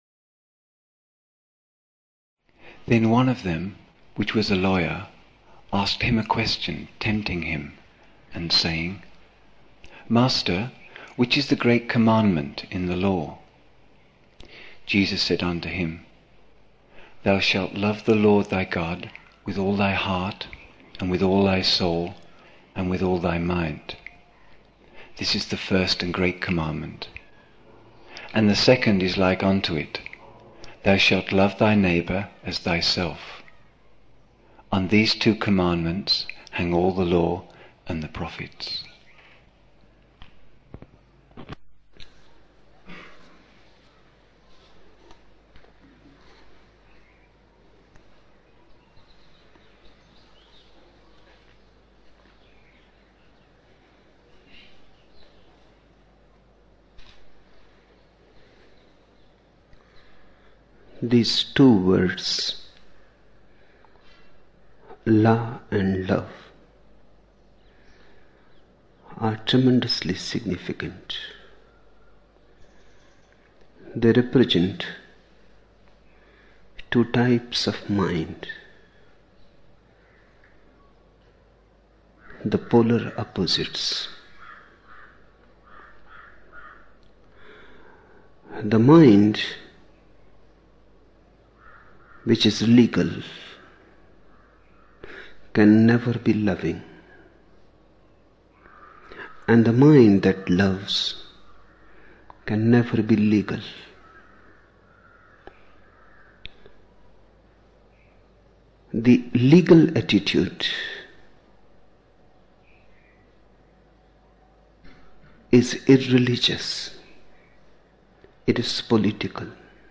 17 December 1975 morning in Buddha Hall, Poona, India